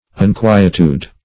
Unquietude \Un*qui"e*tude\, n. Uneasiness; inquietude.